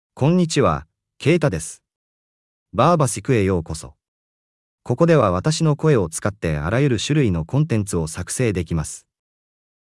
KeitaMale Japanese AI voice
Keita is a male AI voice for Japanese (Japan).
Voice sample
Male
Keita delivers clear pronunciation with authentic Japan Japanese intonation, making your content sound professionally produced.